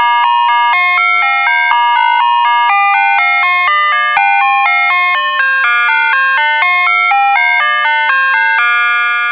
(Sound file is to demonstrate horns sound not loudness)
Chime Music
chime.wav